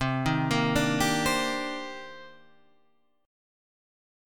C Minor 9th
Cm9 chord {8 6 8 7 8 8} chord